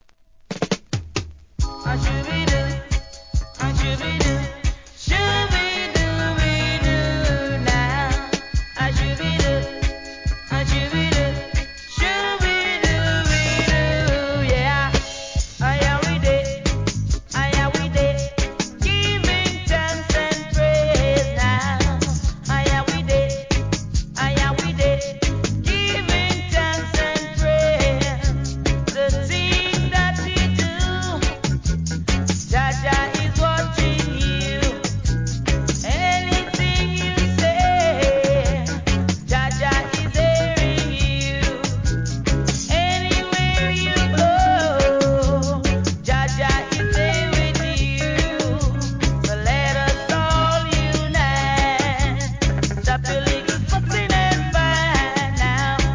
REGGAE
後半DUB接続!